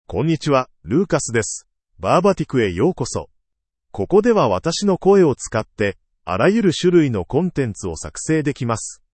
MaleJapanese (Japan)
LucasMale Japanese AI voice
Lucas is a male AI voice for Japanese (Japan).
Voice sample
Lucas delivers clear pronunciation with authentic Japan Japanese intonation, making your content sound professionally produced.